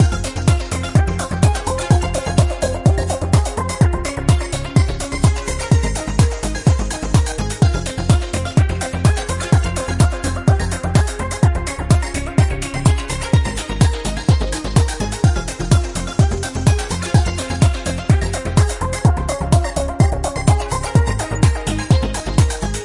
描述：浓密而强烈的立体声
Tag: 循环 激烈 密集